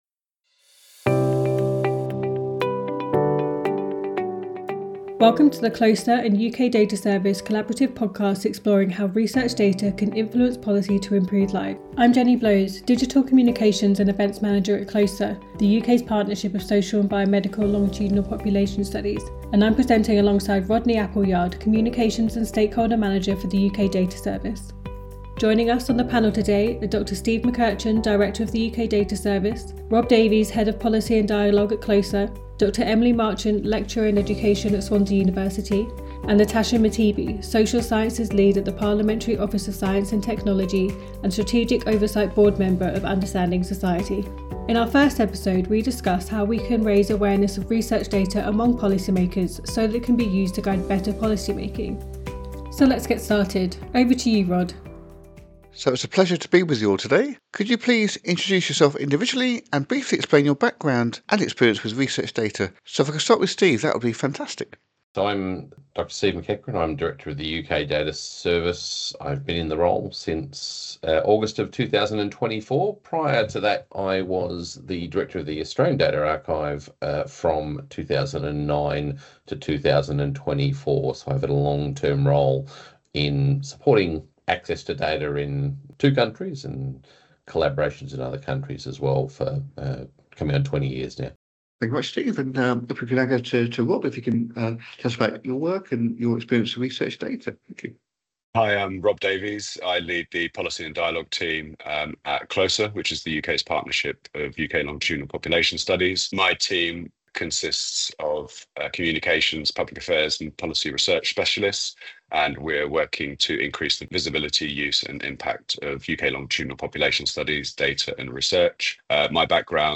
Listen to our new podcast with CLOSER, as we sit down with our expert panel to explore how research data can influence Government policies to improve people's lives.